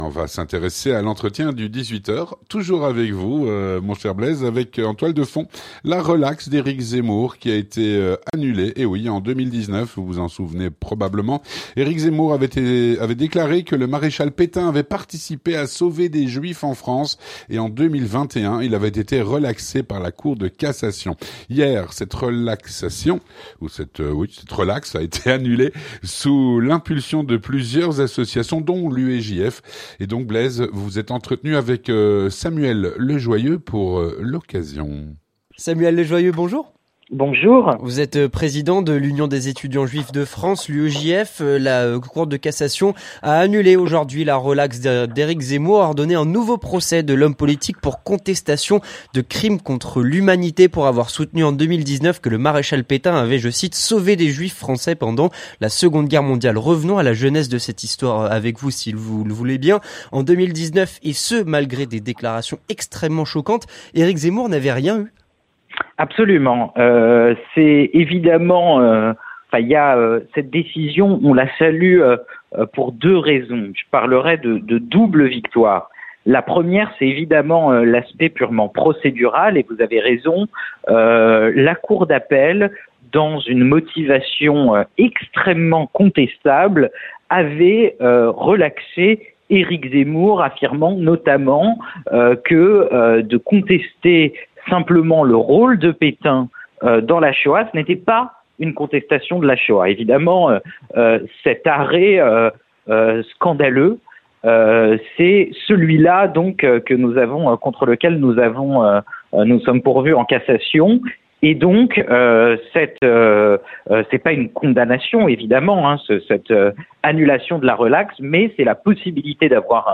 Lentretien du 18H